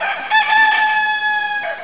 gallo.au